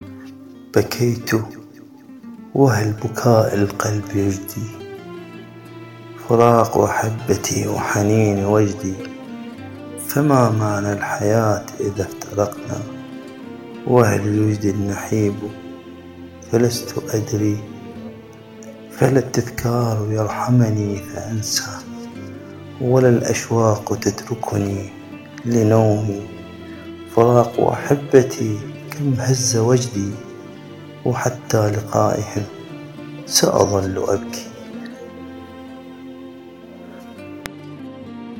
قصيدة بكيتُ ٠٠٠(بصوتي) - منتديات أبعاد أدبية